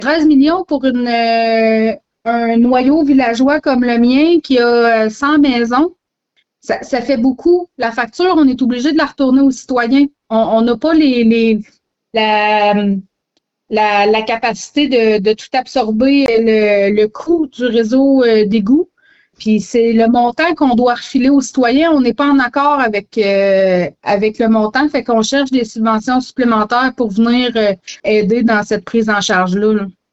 La mairesse, Christine Gaudet, a expliqué que la Municipalité aurait droit à des subventions, mais que la facture serait tout de même salée pour les quelque 450 citoyens. Pour la mairesse, il n’est pas question de leur refiler la totalité de la facture.